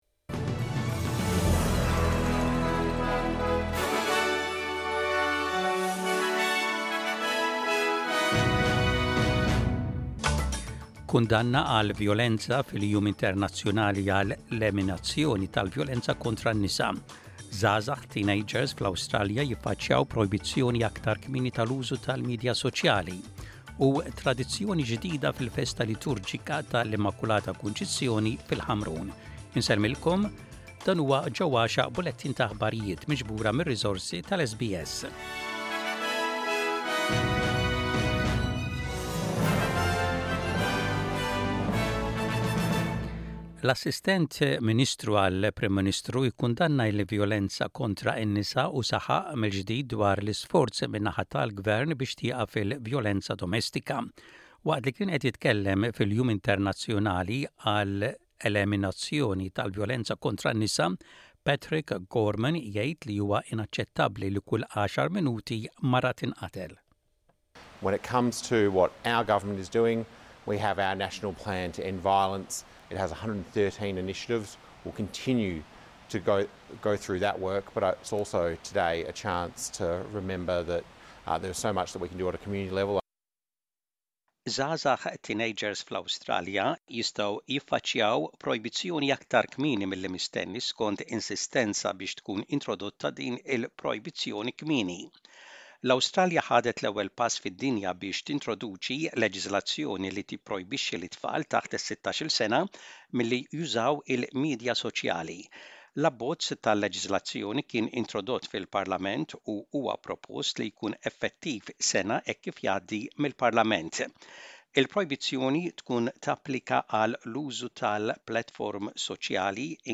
SBS Radio | Aħbarijiet bil-Malti: 26.11.24